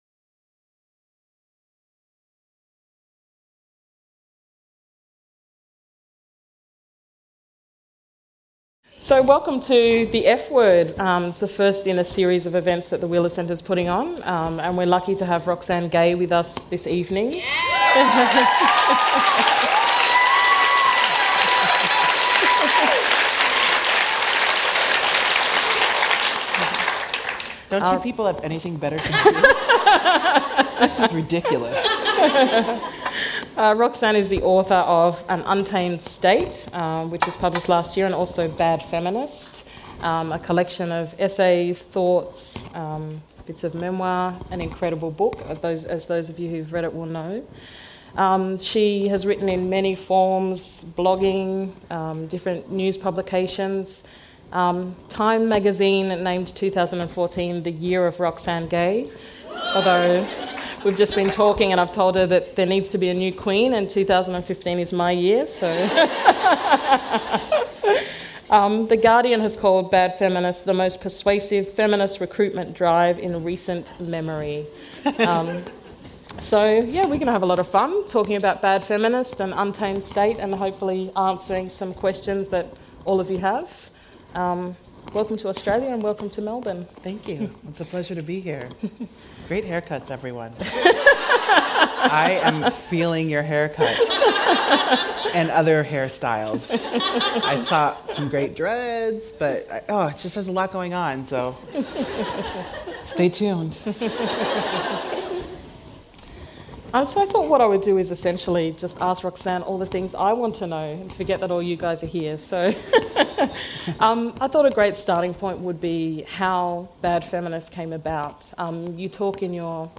Meet Roxane Gay, one of America’s most engaging new literary voices, in conversation with Maxine Beneba Clarke. Their wide-ranging conversation covers inclusive approaches to feminism and activism, as well as the role of race, history and power in informing An Untamed State.